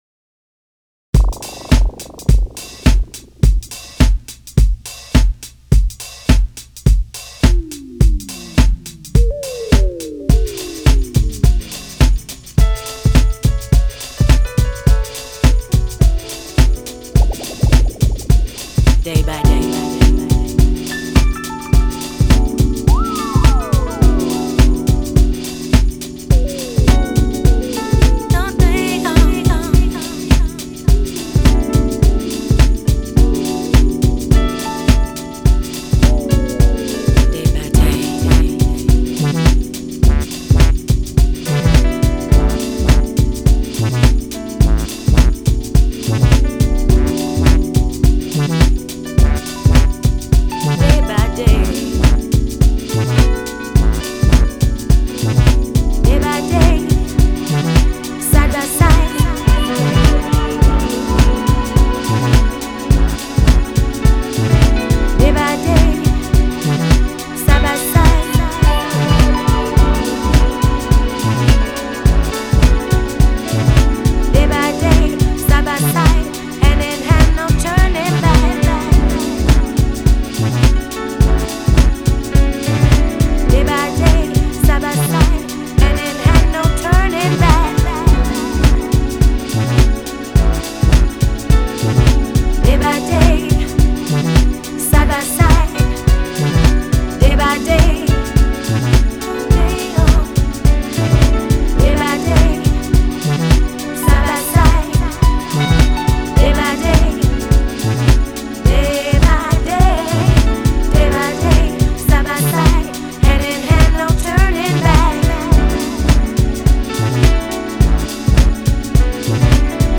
音乐风格：Lounge / Chill Out / Easy Listenung